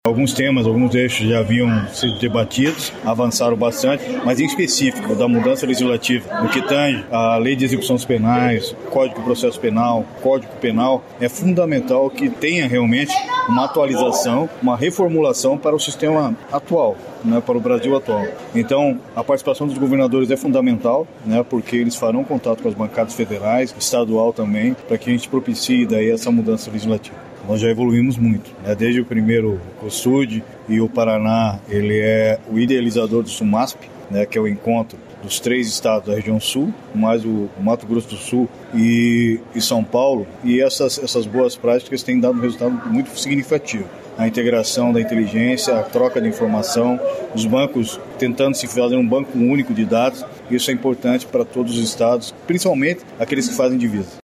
Sonora do secretário da Segurança Pública, Hudson Teixeira, sobre a proposta do Cosud de maior integração interestadual para fortalecimento da segurança